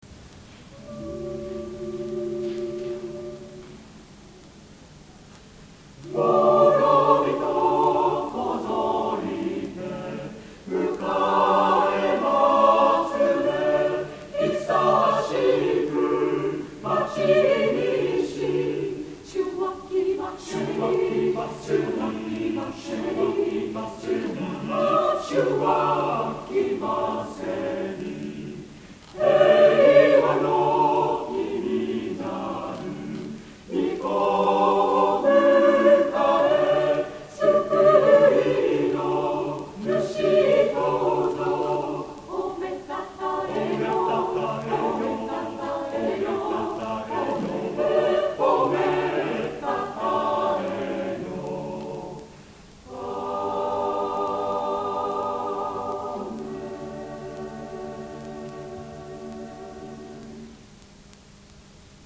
第32回野田市合唱祭
野田市文化会館
もろびとこぞりて　　　　賛美歌／森　友紀